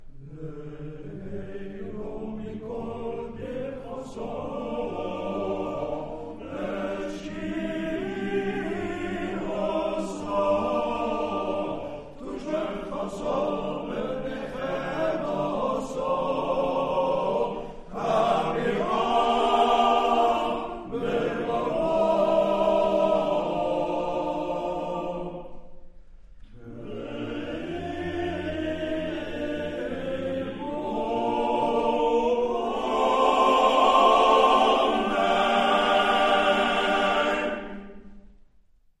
Live at the Concertgebouw, Amsterdam